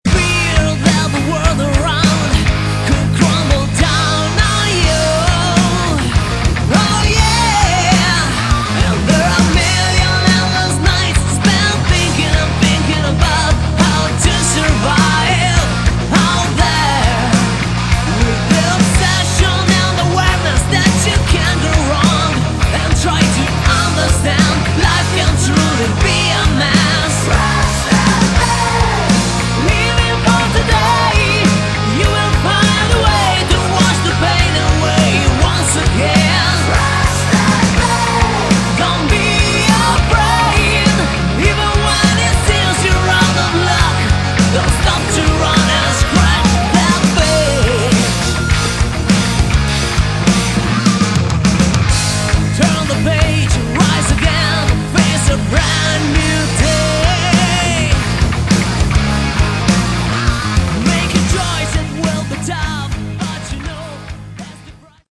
Category: Melodic Rock
vocals
guitars
keyboards
bass
drums
percussion
backing vocals